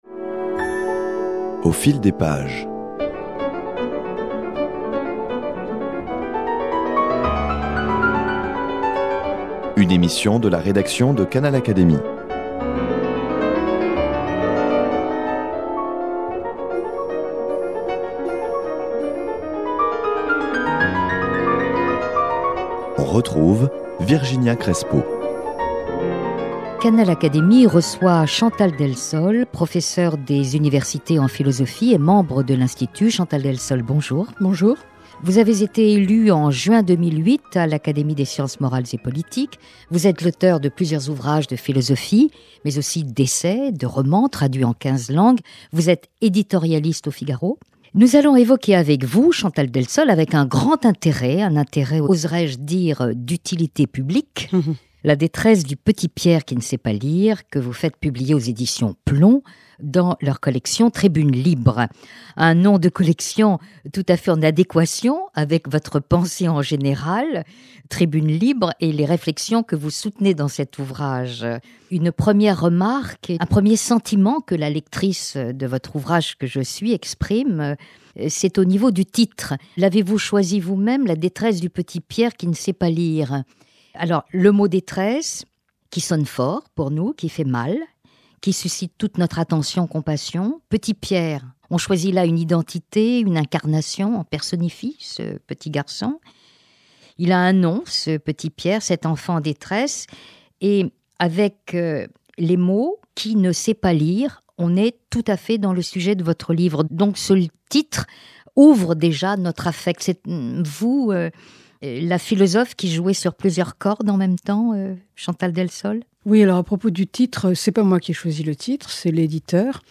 Voici quelques extraits de l'entretien qu'elle a accordé à Canal Académie